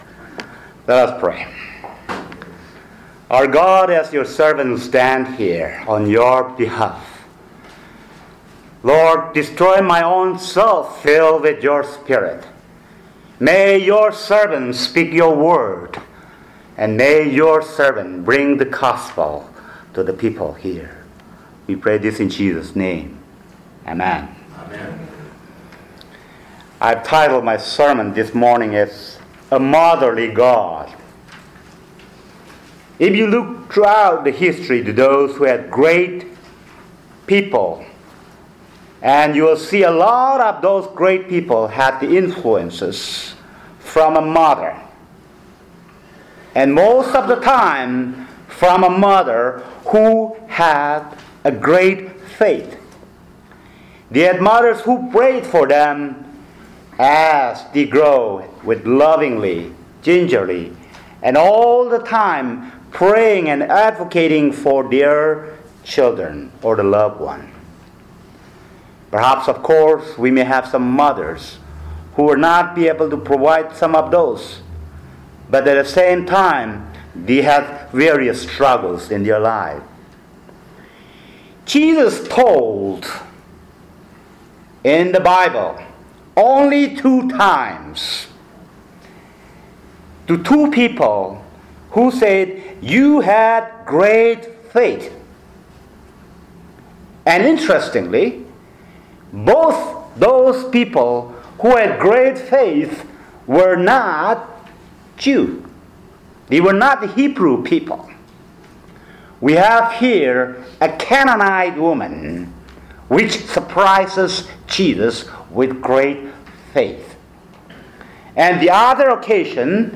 Sermon: A Motherly God | First Baptist Church, Malden, Massachusetts